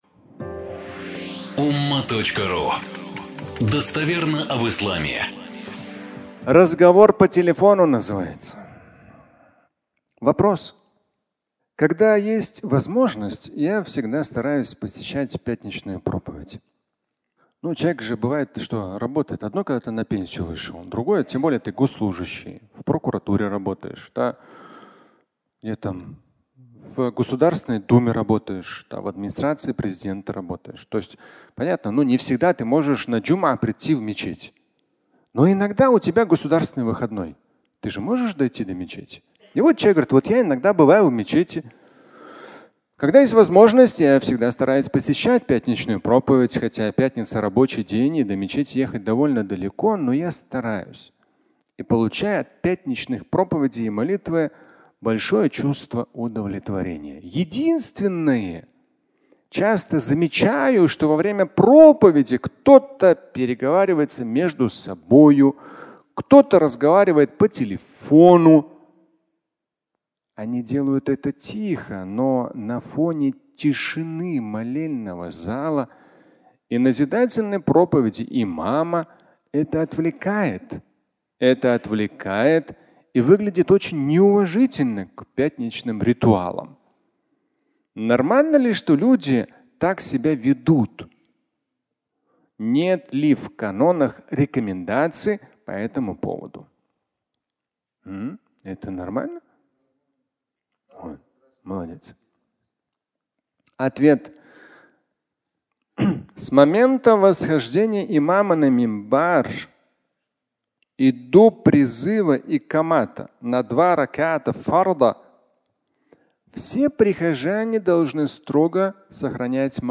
Разговор по телефону (аудиолекция)
Аудио статья
Пятничная проповедь